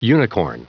Prononciation du mot unicorn en anglais (fichier audio)
Prononciation du mot : unicorn